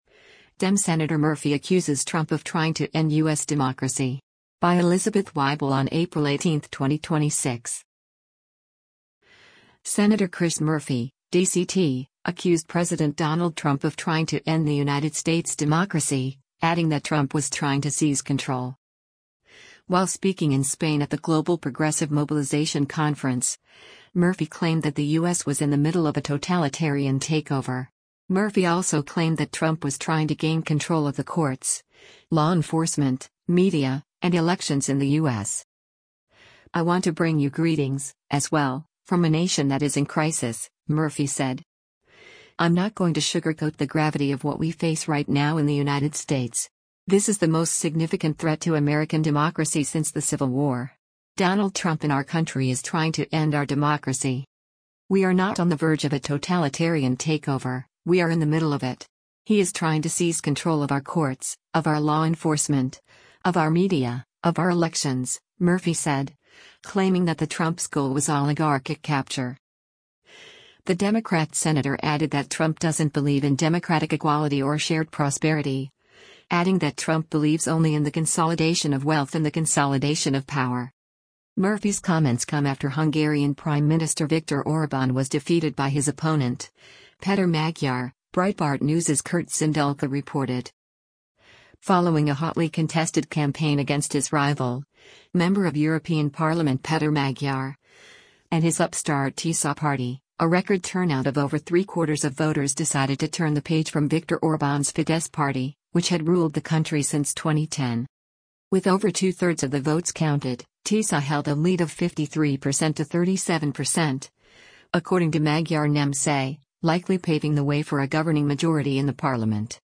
While speaking in Spain at the Global Progressive Mobilisation conference, Murphy claimed that the U.S. was “in the middle” of a totalitarian takeover.